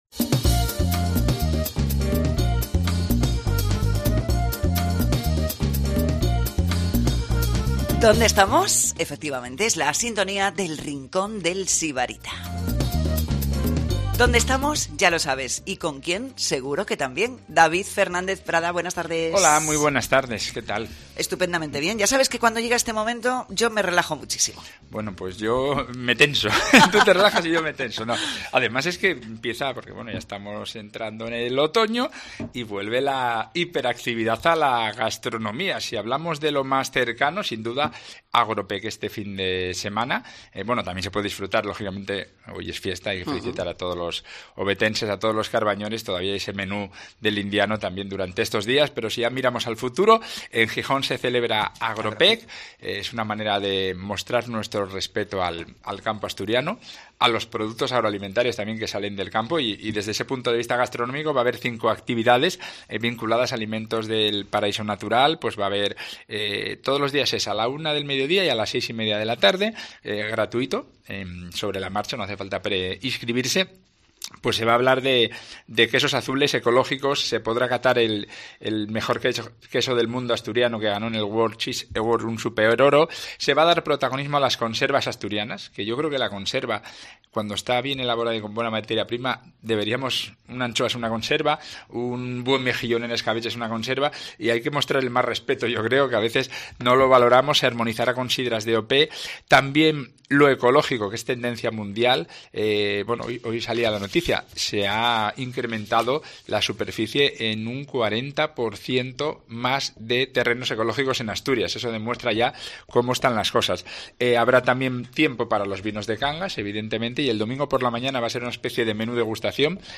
La cita semanal con la actualidad gastronómica asturiana en Cope Asturias